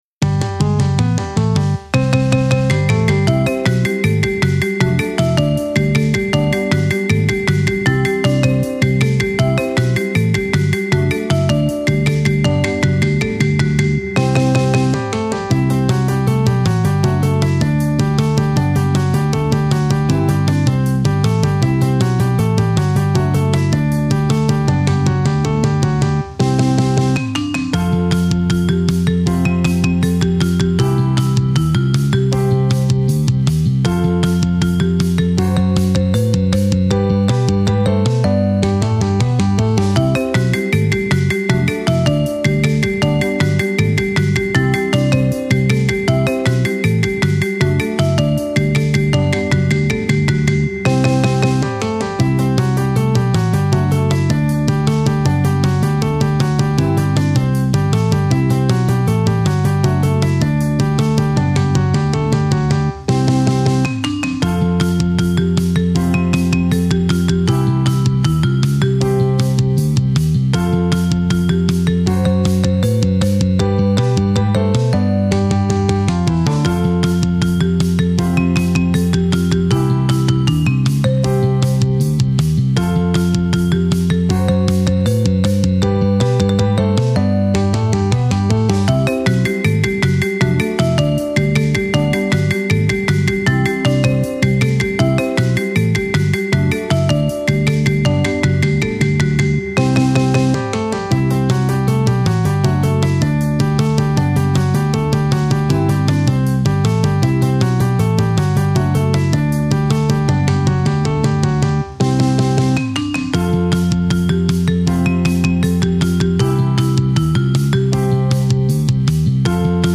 【用途/イメージ】　アニメ　楽しい　陽気